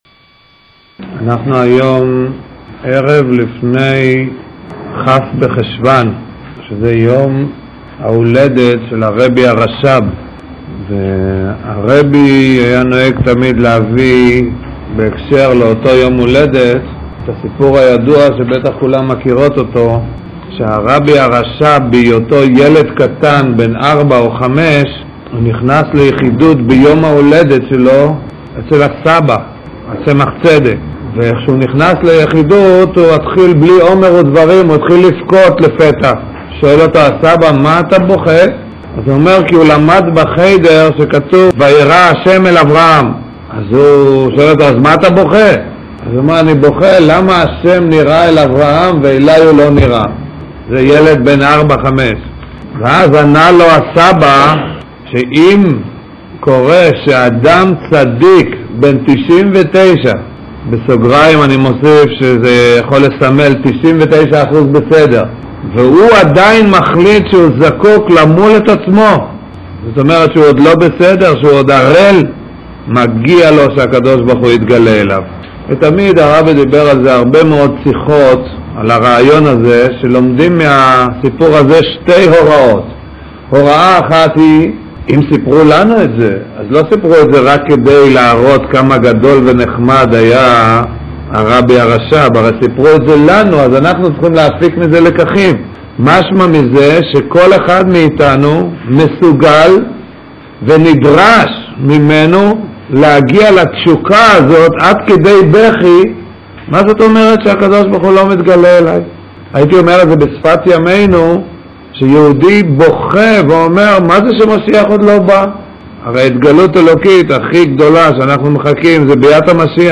השיעורים מקיפים את ה-17 פרקים הראשונים של הקונטרס עלות הדיסק: 20 ש"ח. COL מפרסם את ההרצאה הראשונה על שלושת הפרקים הראשונים של הקונטרס.